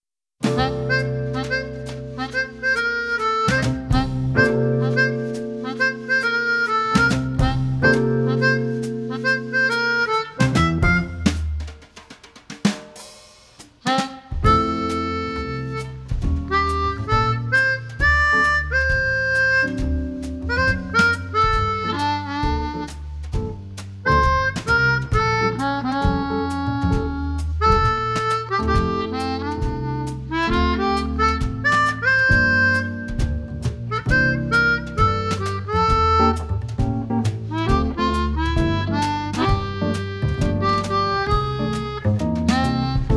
New Orleans  Blues